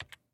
Звуки клавиатуры
Тихий звук нажатия клавиши на клавиатуре